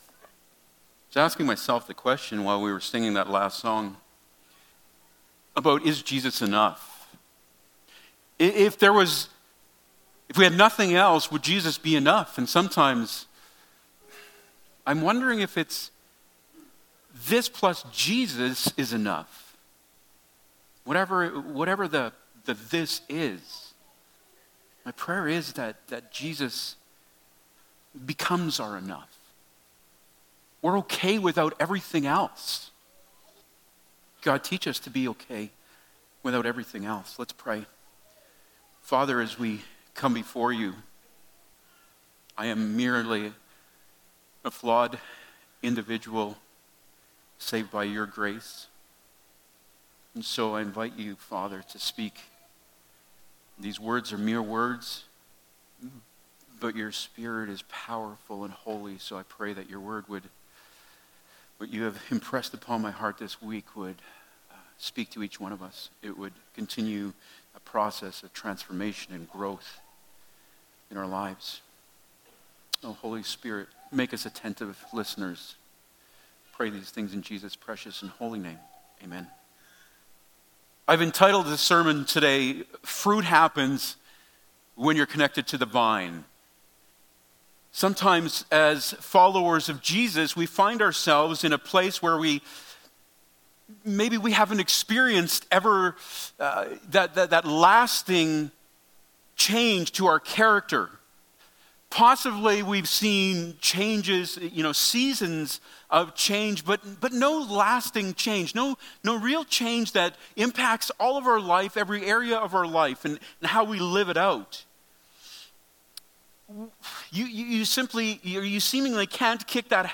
Passage: John 15:1-17 Service Type: Sunday Morning « Don’t Bend for a Trend